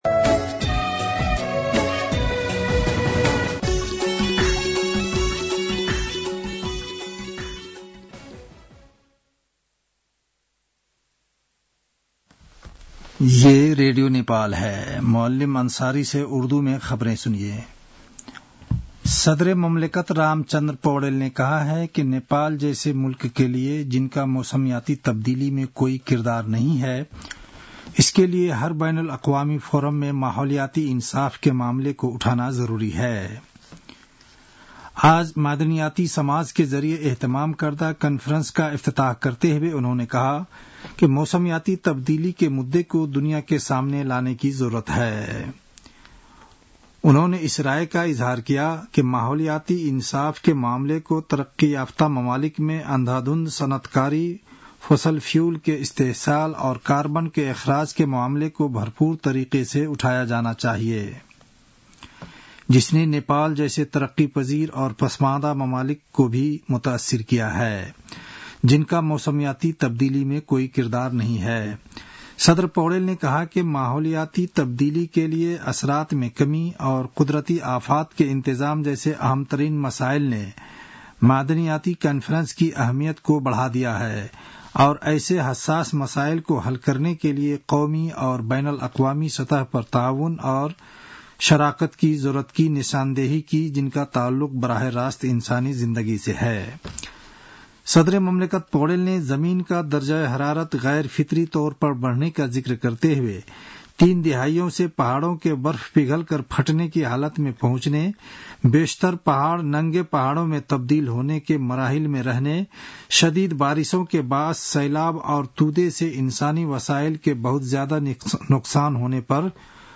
उर्दु भाषामा समाचार : २७ फागुन , २०८१